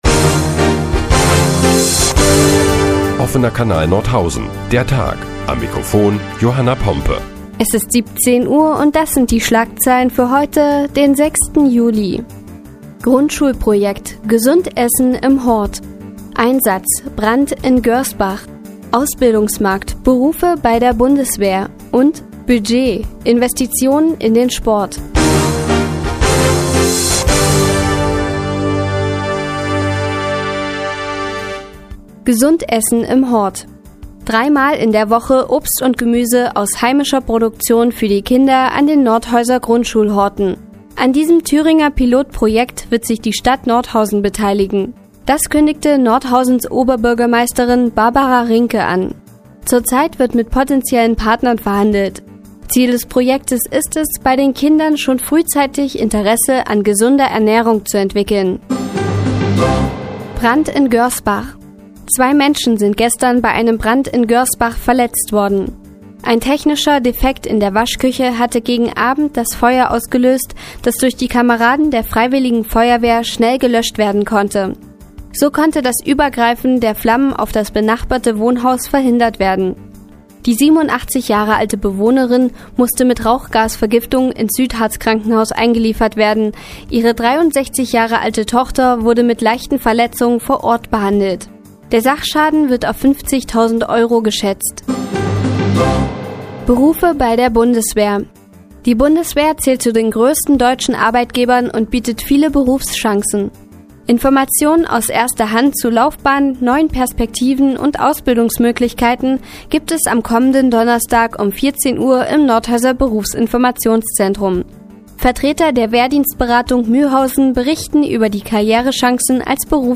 Die tägliche Nachrichtensendung des OKN ist nun auch in der nnz zu hören. Heute geht es unter anderem um gesundes Essen im Hort und Brand in Görsbach.